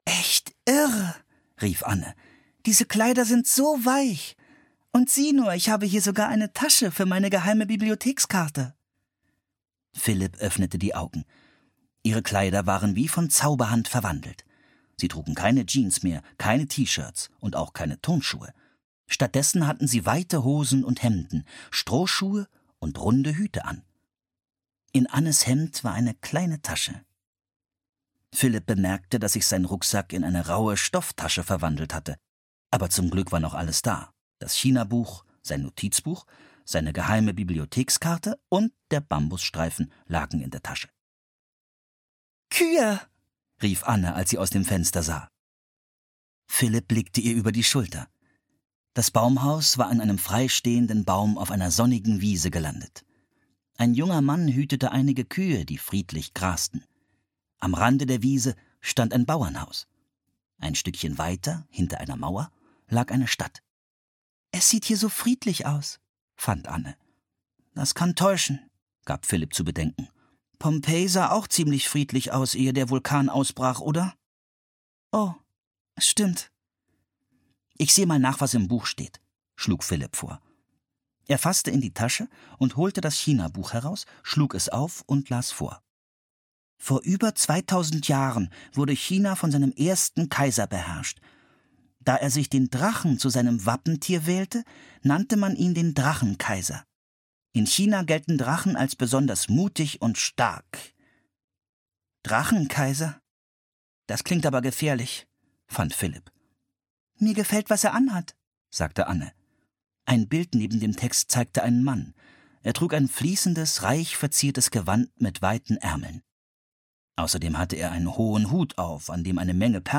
Im Land der Drachen (Das magische Baumhaus 14) - Mary Pope Osborne - Hörbuch